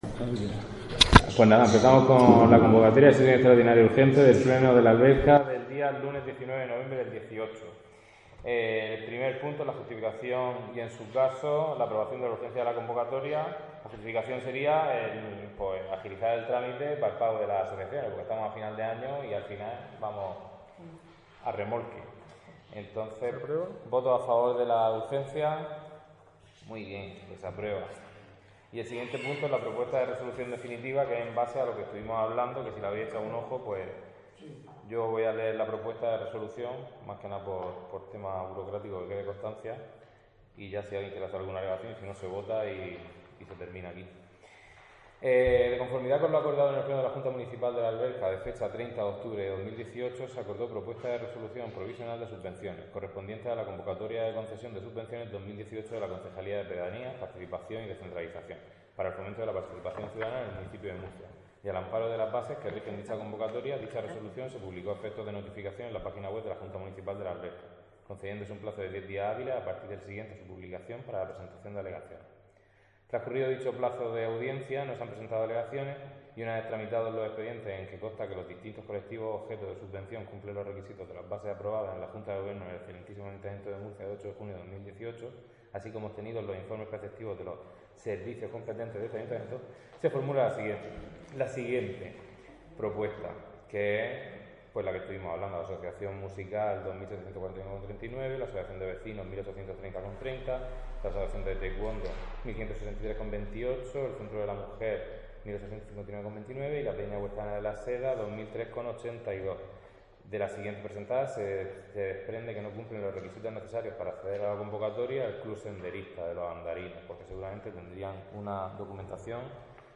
AUDIO PLENO ORDINARIO JUNTA MPAL. DE LA ALBERCA 08/11/2018